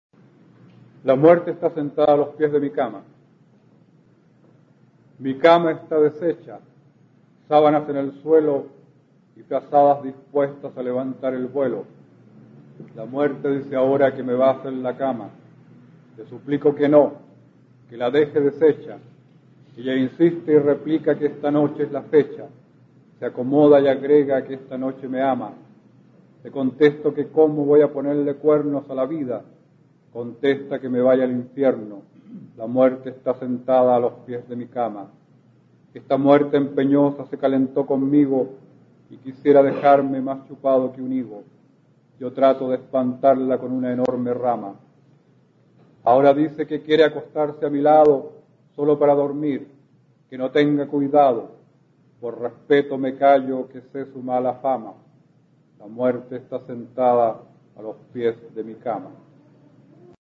Aquí podrás escuchar al poeta chileno Óscar Hahn, perteneciente a la Generación del 60, recitando su poema La muerte está sentada a los pies de mi cama, del libro "Arte de morir" (1977).
Poema